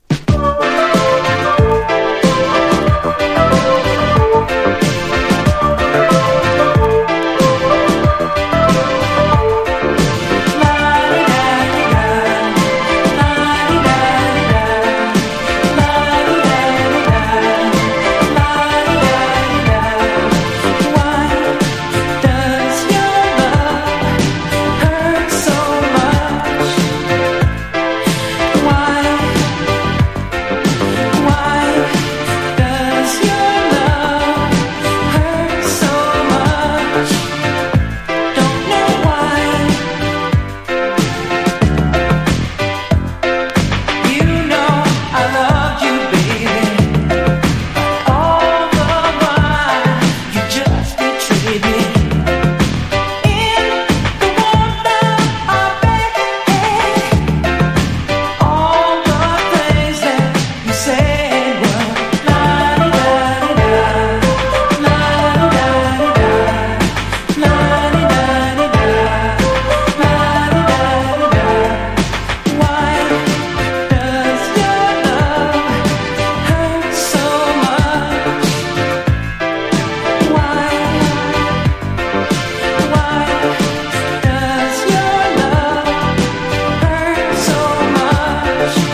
バレアリック・クラシック！！